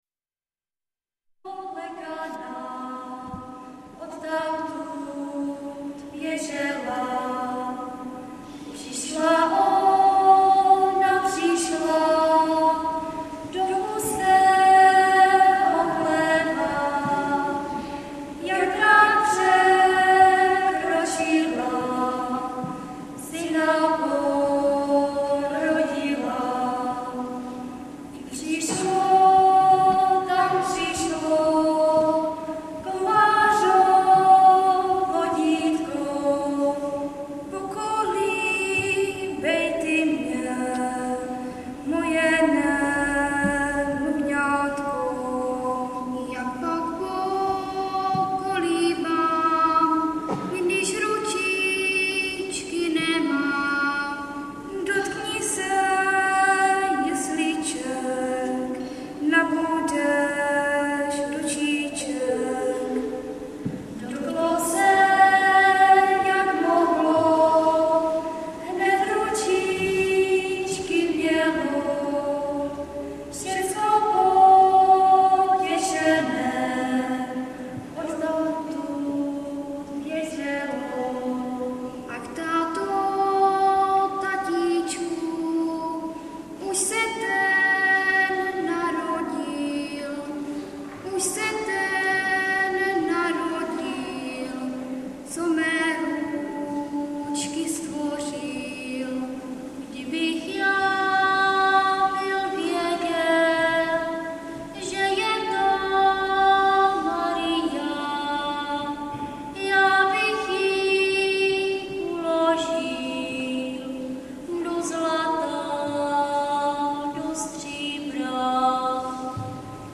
ADVENTNÍ KONCERT
Březová … kostel sv. Cyrila a Metoděje ... neděla 9.12.2007
... pro přehrátí klepni na názvy písniček...bohužel ně skomírala baterka v MD ...